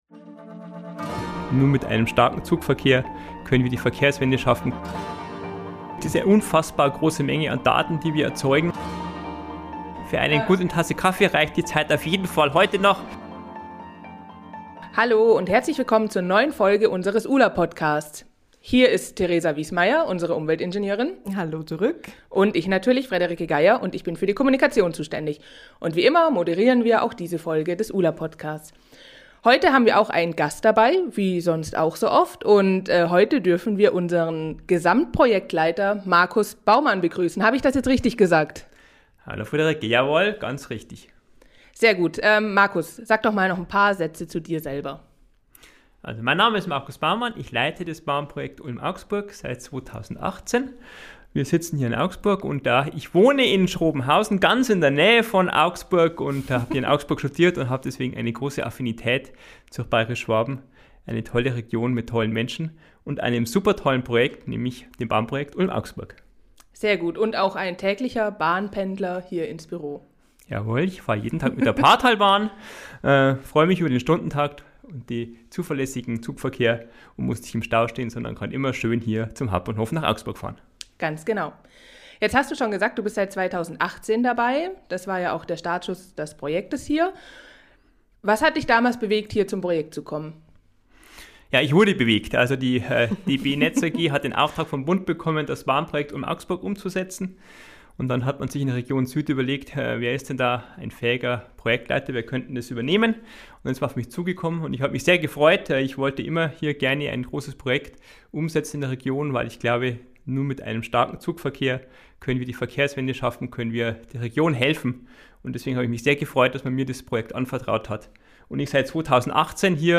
Ein Gespräch über das, was so viele Mitarbeiterinnen und Mitarbeiter den ganzen Tag machen, wie viele Minuten man für einen Kaffee braucht und schwierige aber notwendige Diskussionen.